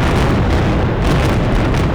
boom.wav